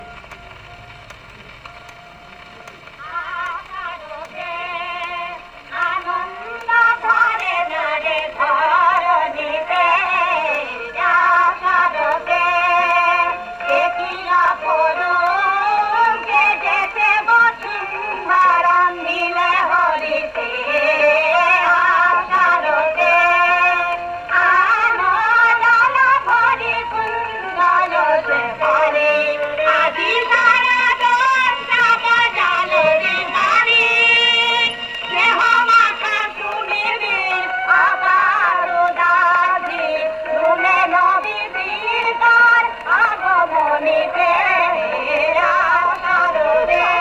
রেকর্ড-নাটিকা: ‘সুরথ উদ্ধার’
• বিষয়াঙ্গ: প্রকৃতি [শরৎ] নাট্য-পালা
• সুরাঙ্গ: স্বকীয় বৈশিষ্ট্যের সুর